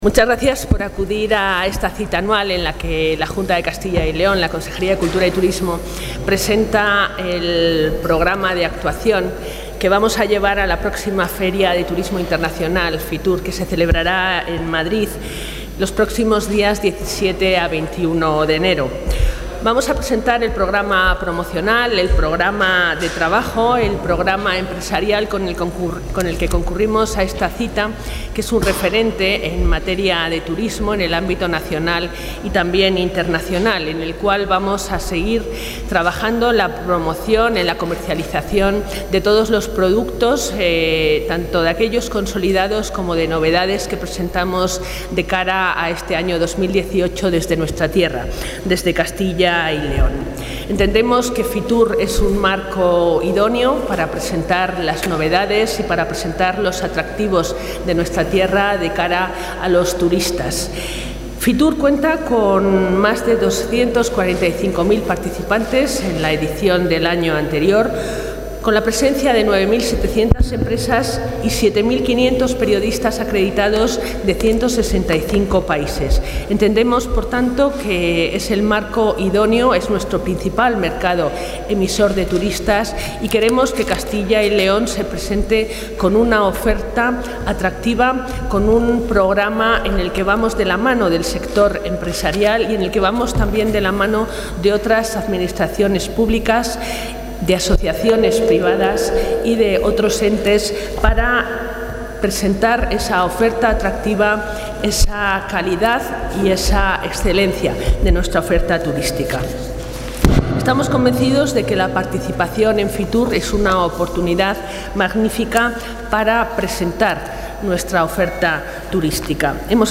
Intervención de la consejera de Cultura y Turismo.